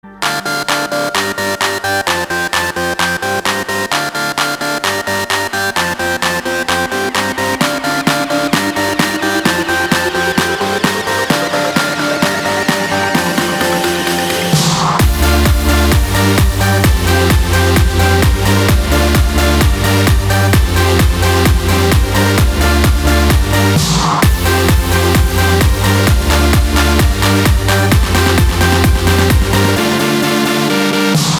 • Качество: 256, Stereo
без слов
club
house
electro
Жанр: Electro / House